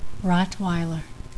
Pronunciation
ROTT-why-ler